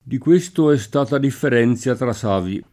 [ differ $ n Z a ]